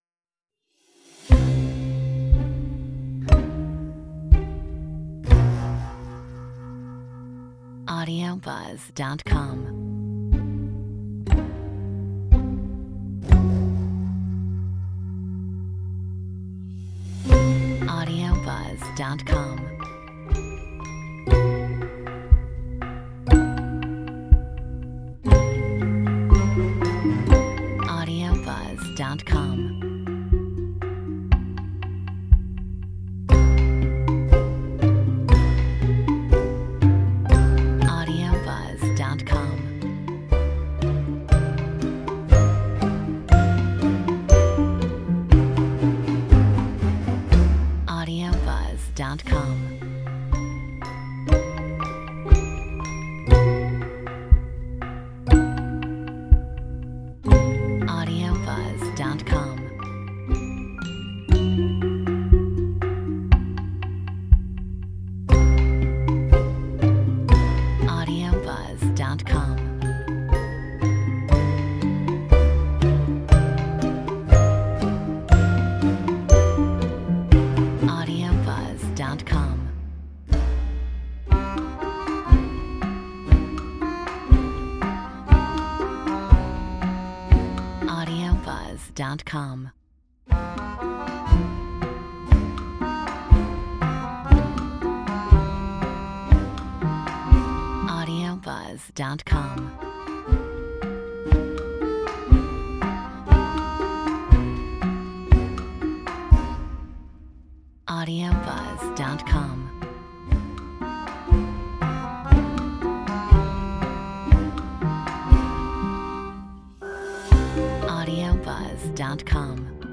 Metronome 60